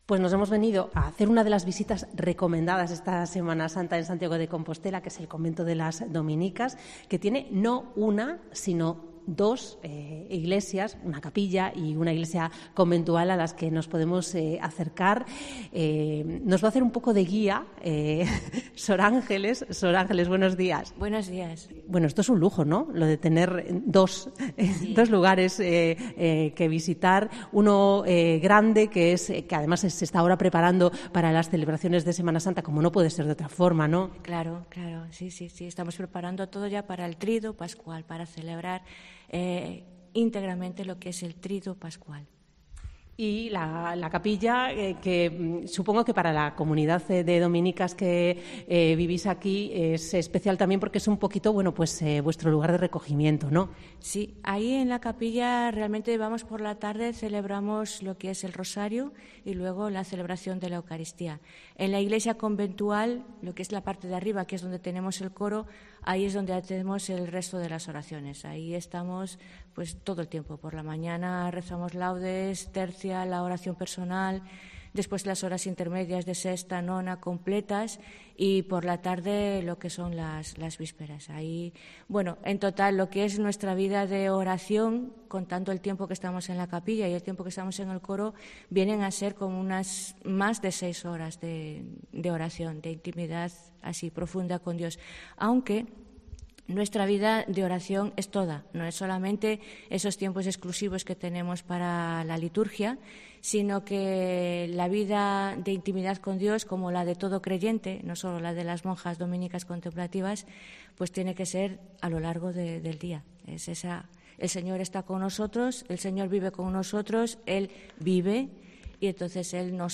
La visita a las iglesias y monasterios es una de las propuestas para vivir la Semana Santa compostelana. Asomamos el micrófono de Cope al convento de...